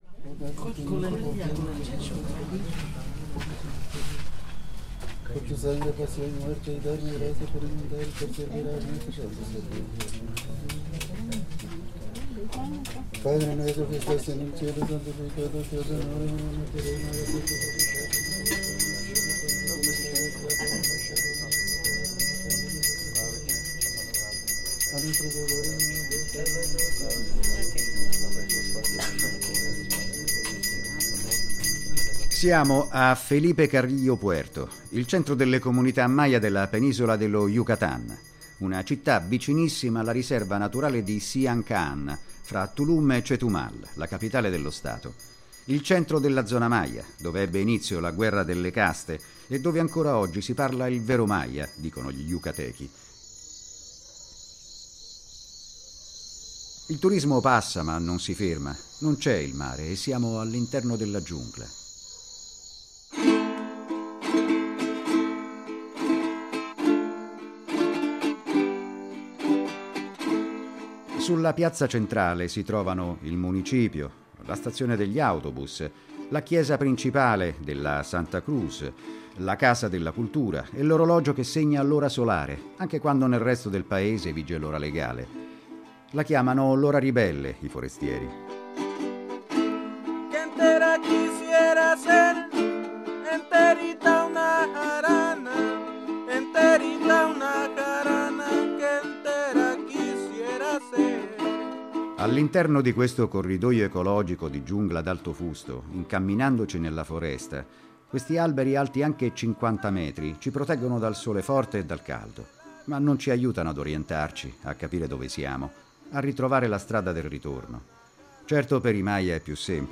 Le registrazioni effettuate all’interno dei centri cerimoniali sono delle fonti etnografiche uniche.